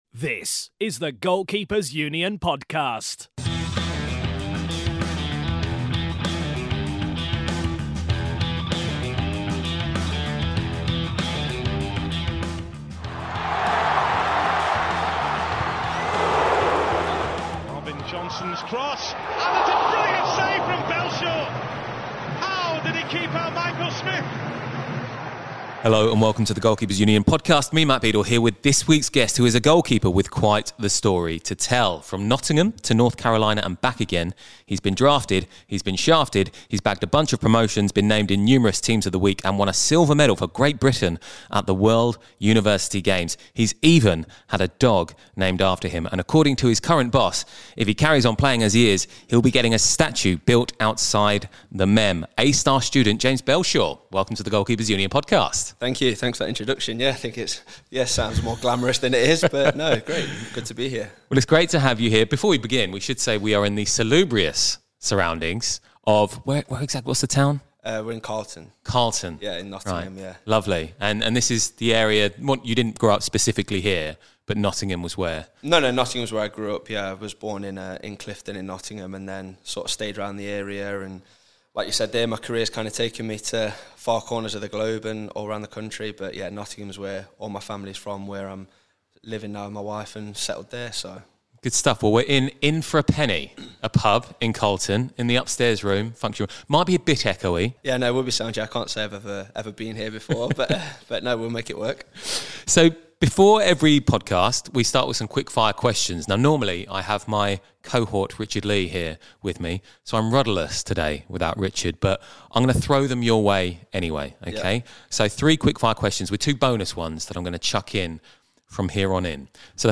This week's feature interview provides yet another take on the route into professional goalkeeping.